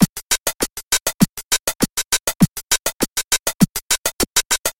打击乐环形物
描述：一个打击乐循环，速度为100bpm。
Tag: 100 bpm Glitch Loops Percussion Loops 826.92 KB wav Key : Unknown